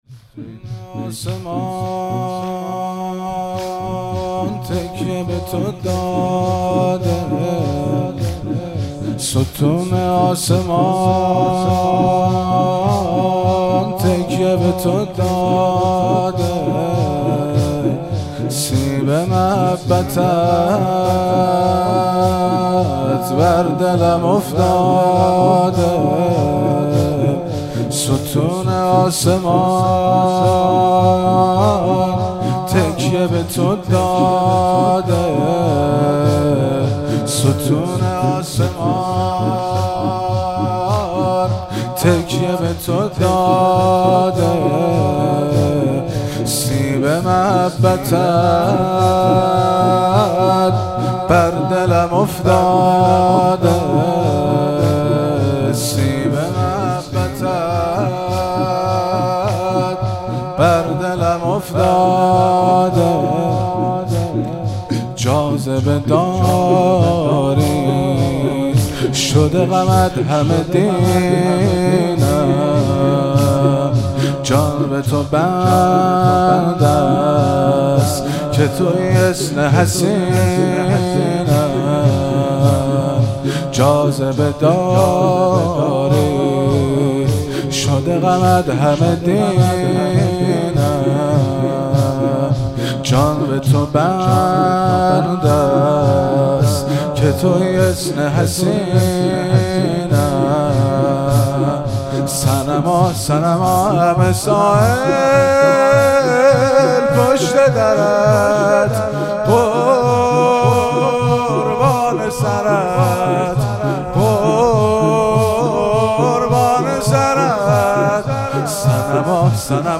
زمینه- ستون آسمان تکیه به تو داده